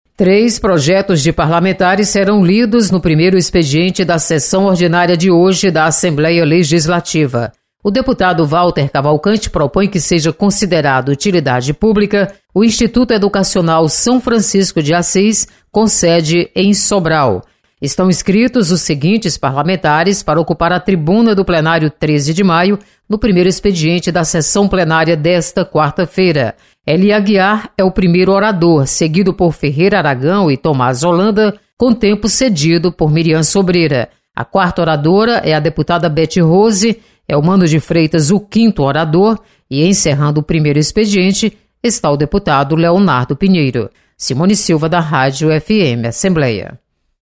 Você está aqui: Início Comunicação Rádio FM Assembleia Notícias Expediente